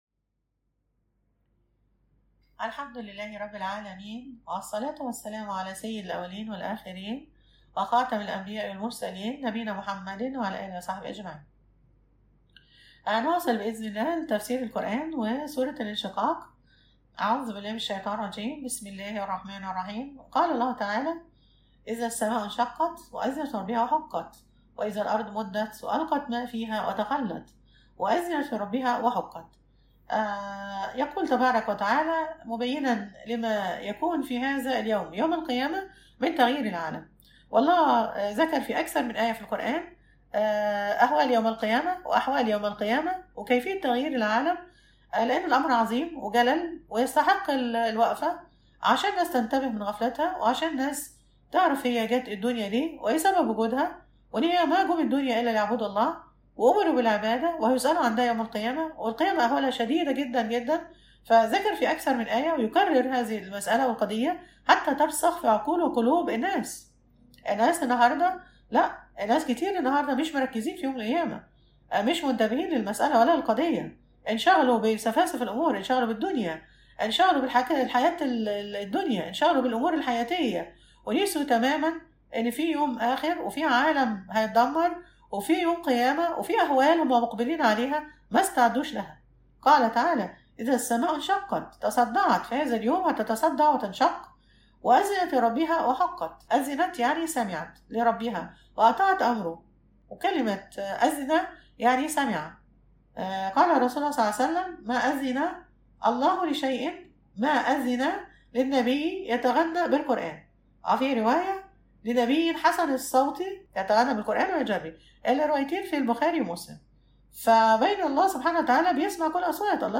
المحاضرة الثامنة_ سورة الانشقاق